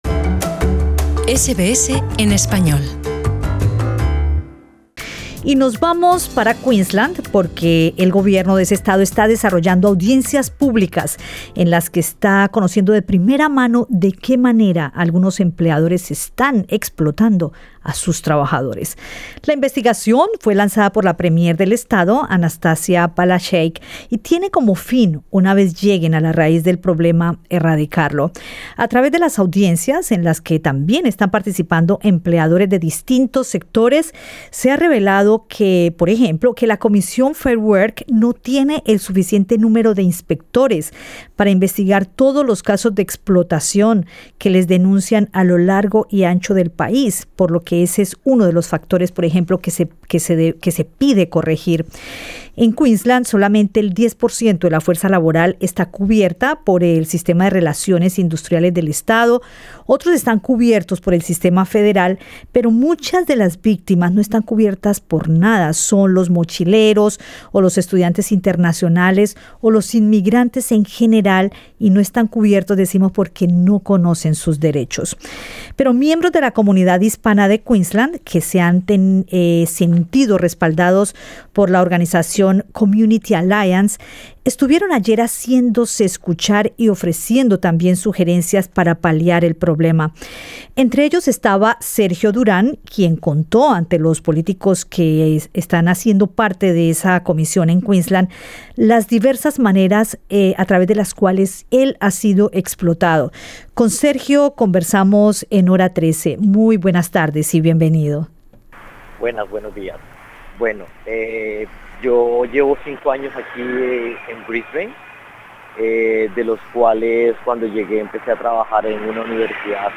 (Escucha el podcast con la entrevista